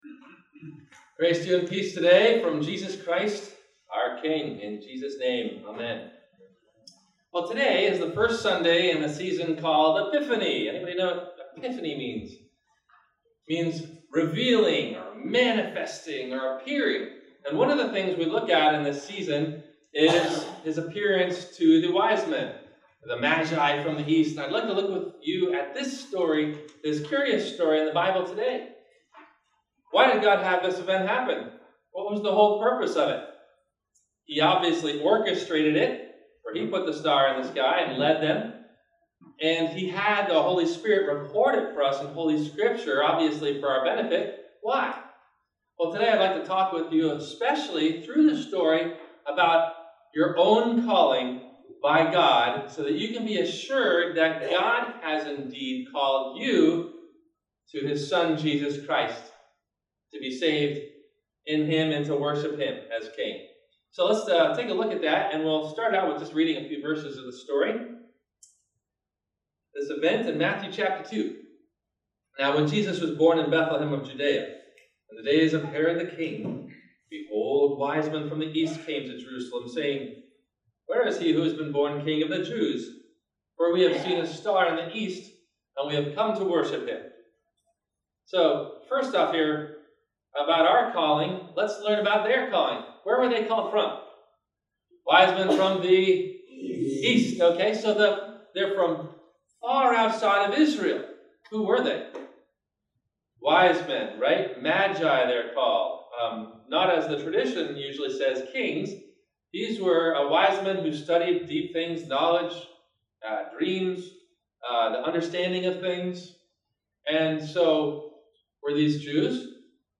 To Follow That Star – Sermon – January 12 2014